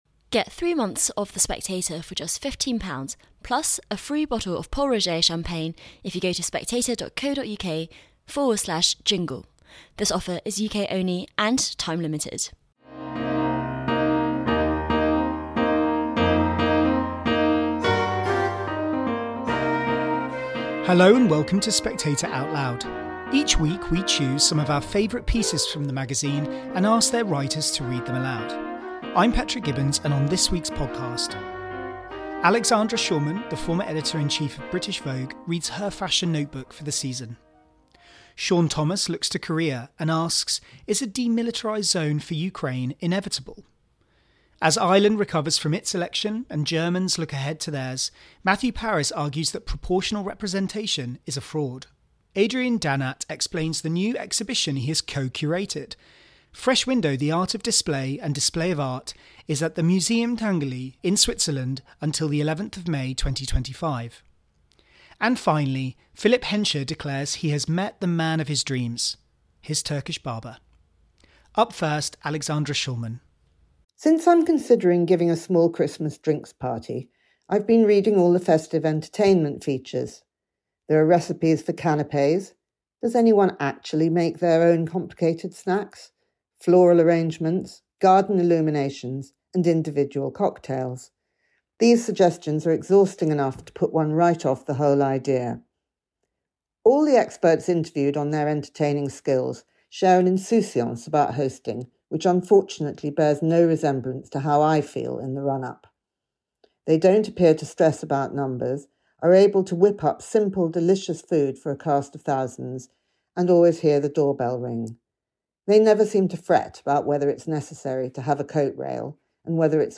Spectator Out Loud: Alexandra Shulman reads her fashion notebook